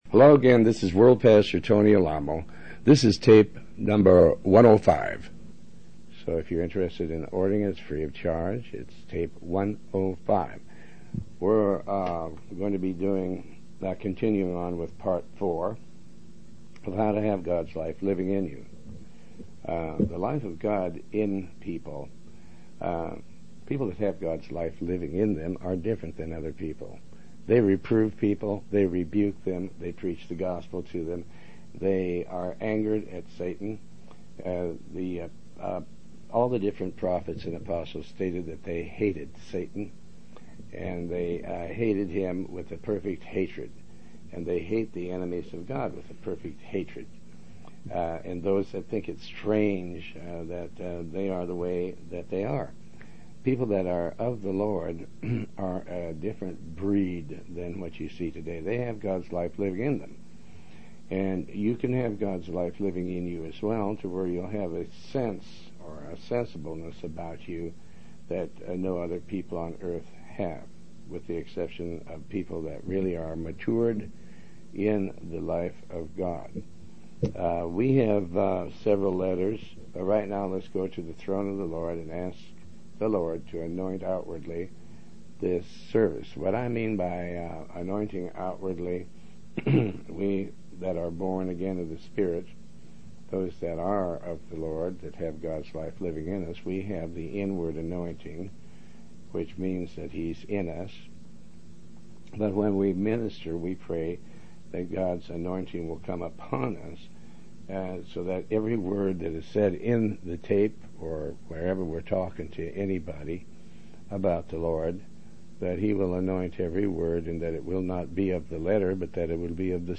Talk Show
Show Host Pastor Tony Alamo